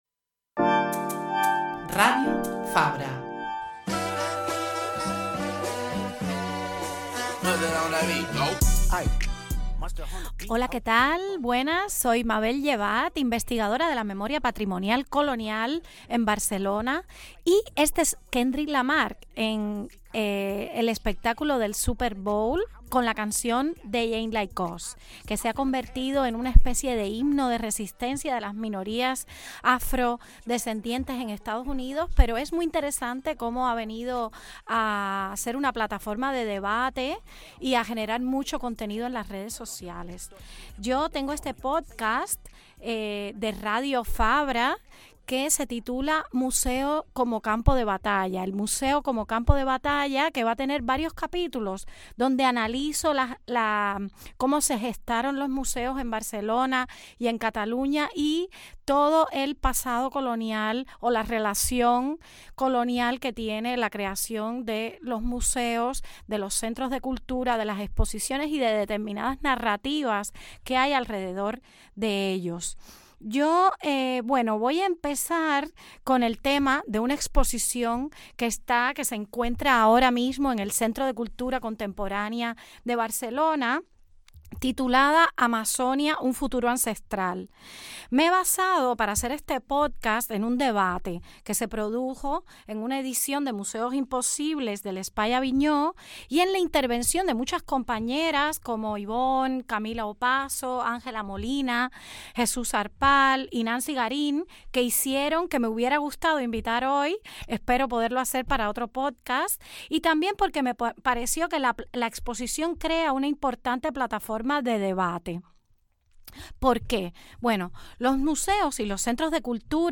El primer episodio de 'El museo como campo de batalla' parte de la exposición 'Amazonia, el futuro ancestral' que acoge el CCCB para reflexionar en torno a narrativas que se construyen según la mirada europea de territorialidades que son dibujadas de manera hiperreal o estereotipada. También recoge un interesante debate programado por el Espai Avinyó en su ciclo 'Museus (Im)possibles', también en el CCCB.